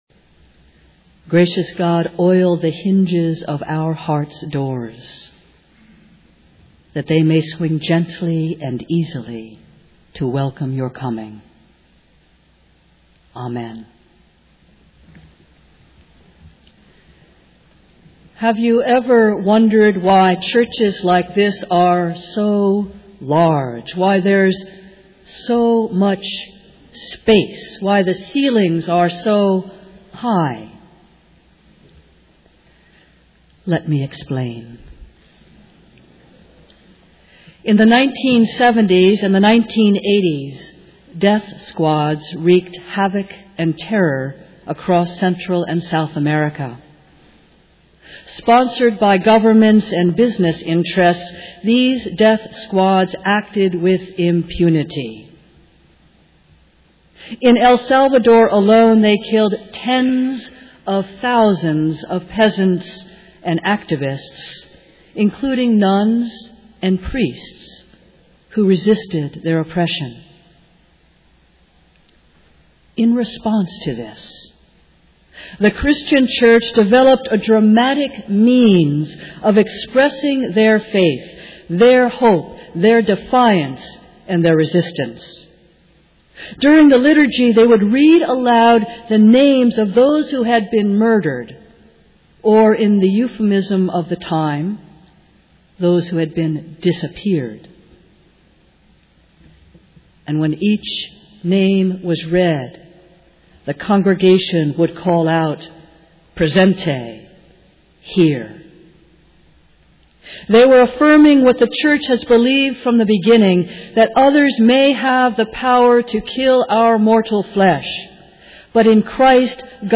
Festival Worship